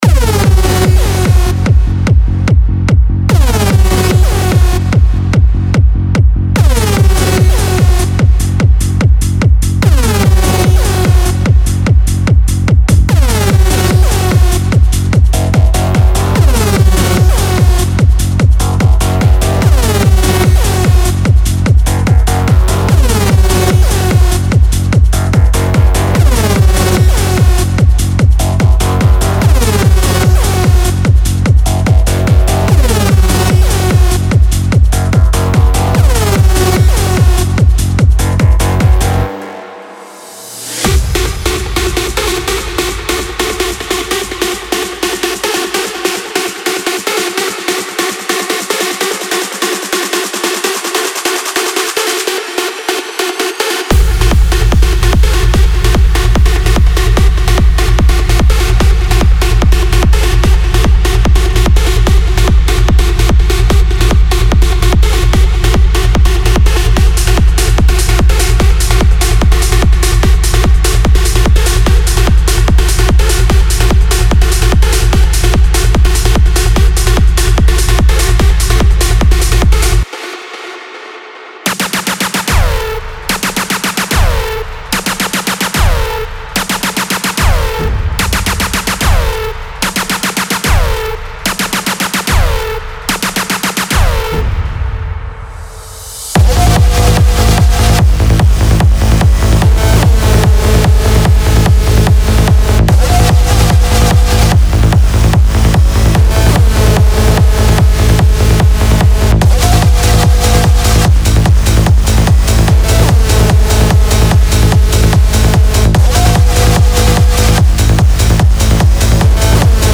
• 11 Bass Presets
• 21 Lead Presets
• 3 Pad Presets
• 6 Pluck Presets
• Recorded in 147 Bpm & Key labelled